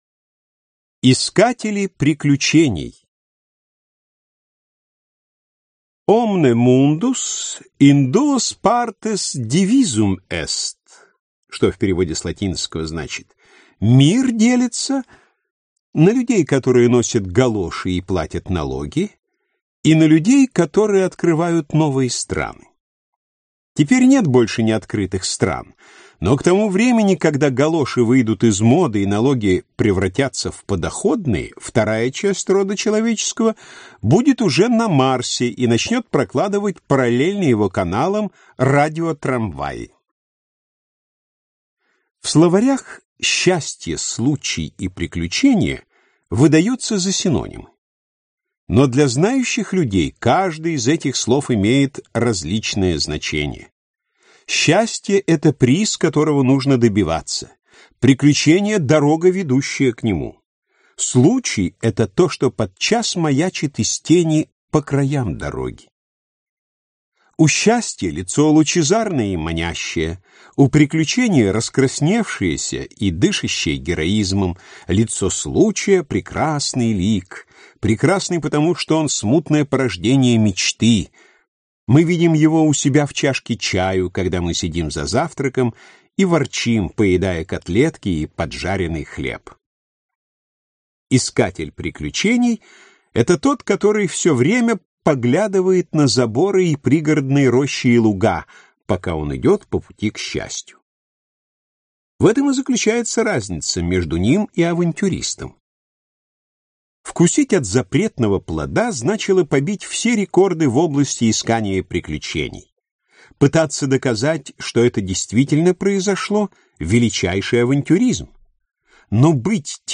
Аудиокнига Новеллы. Вып. 2 | Библиотека аудиокниг